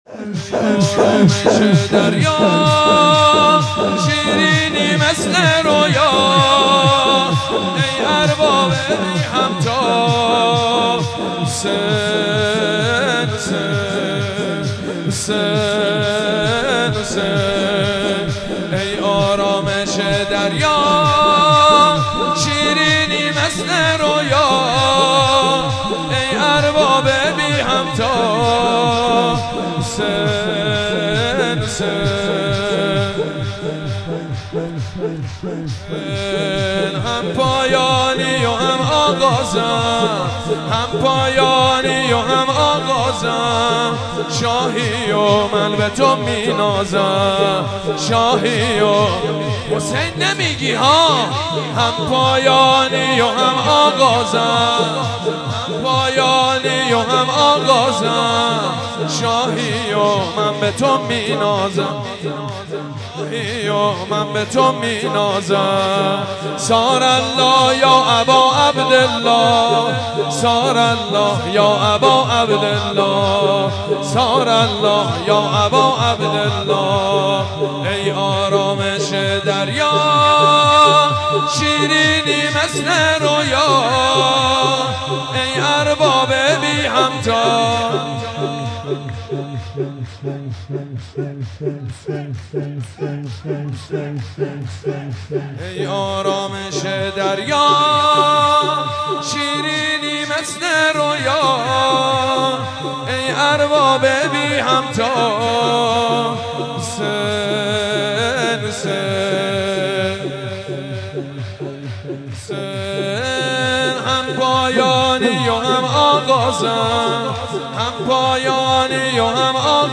شام میلاد امام رضا(ع) در هیئت مادر سادات مشهد
سرود
روضه پایانی